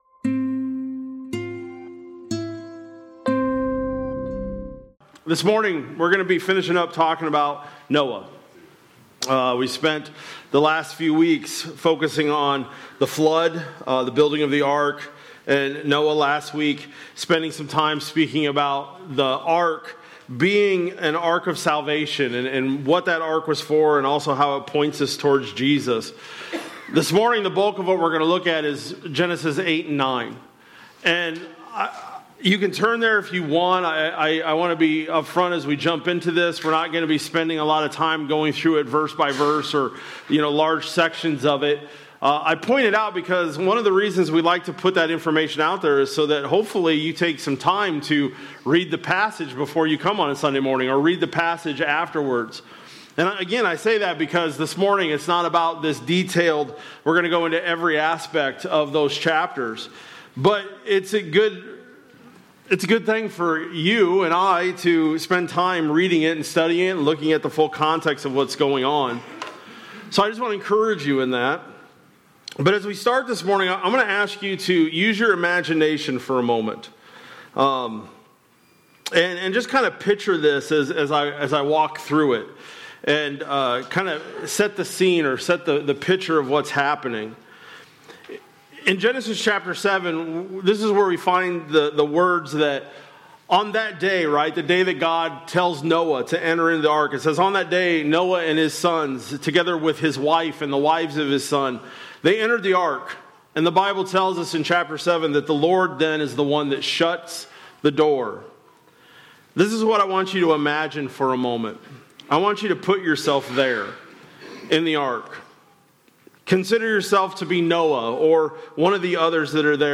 March-22-26-Sermon-Audio.mp3